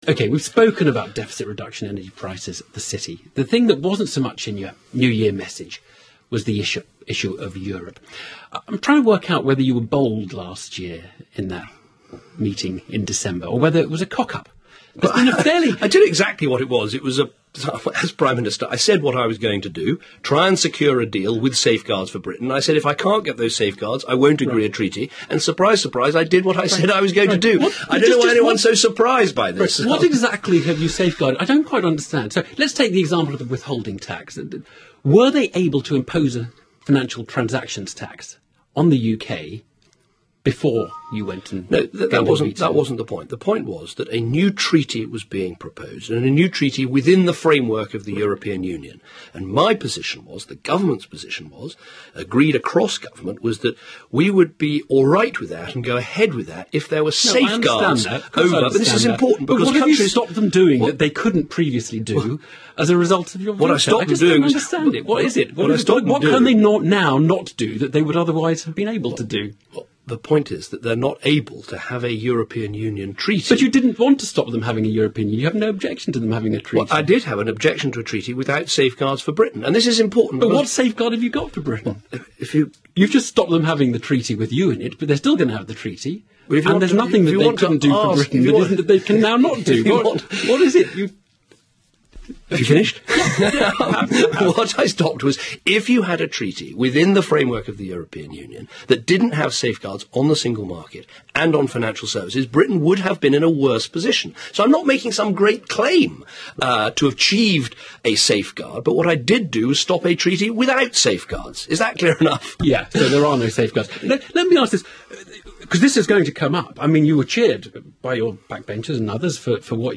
David Cameron made an appearance on the Today programme on 6 January 2012 and once again defended his veto at the EU. Clearly his position has not changed, but Evan Davis did stress once again his own perplexity when it comes to what was actually achieved.